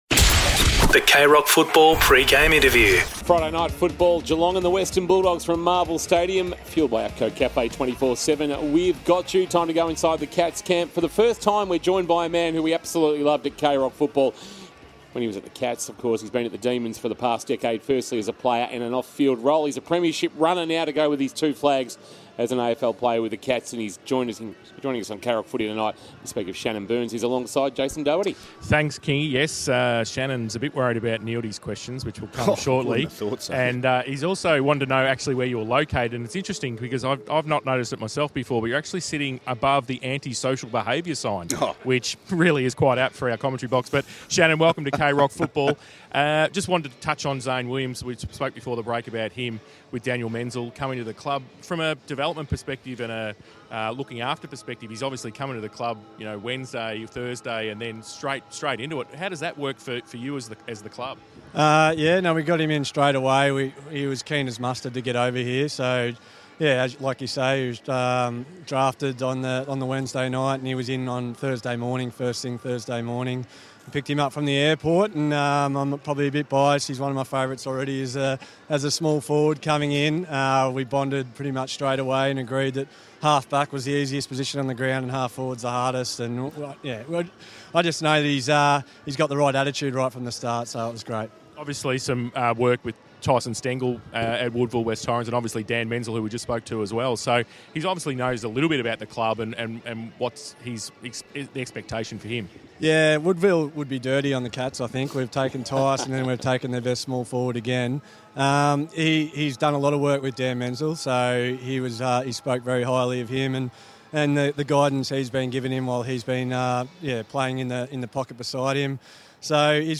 2022 - AFL ROUND 12 - WESTERN BULLDOGS vs. GEELONG: Pre-match Interview